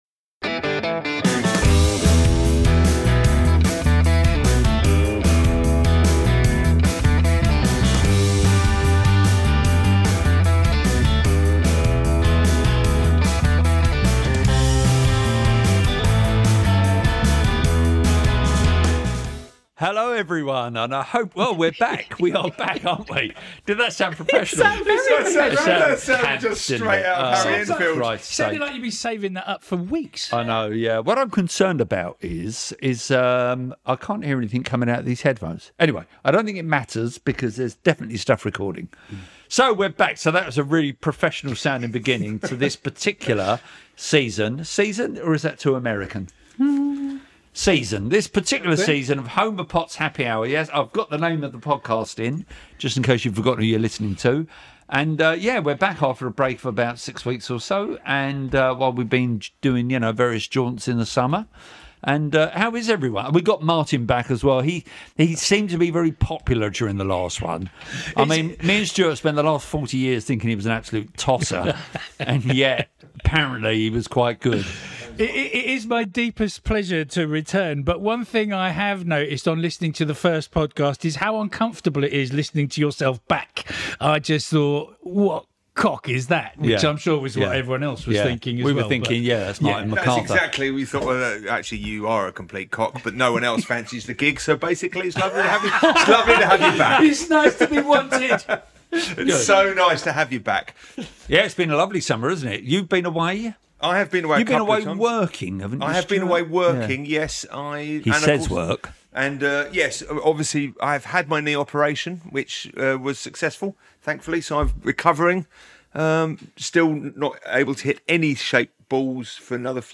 Fortnightly magazine show, full of showbiz gossip, sports chat, nostalgia, special guests and fun.